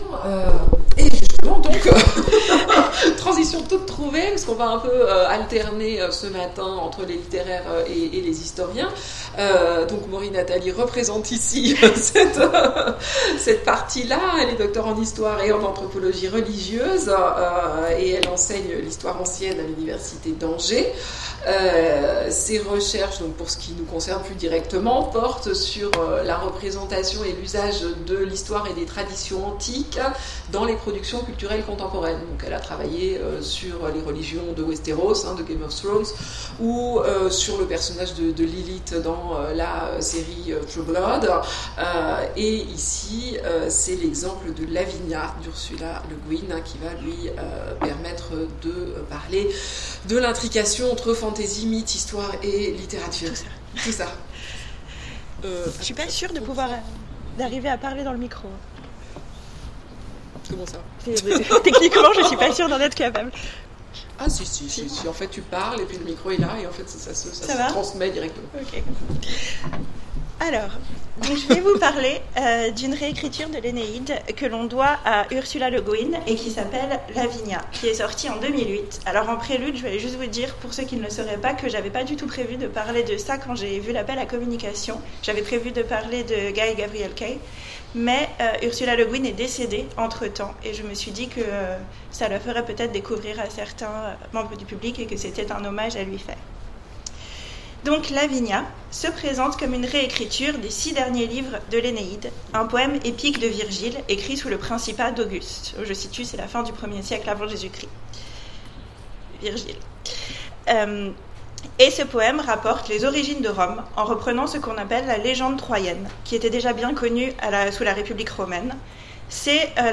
Colloque universitaire 2018 : La fantasy comme nouveau mythe
Mots-clés Mythologie Conférence Partager cet article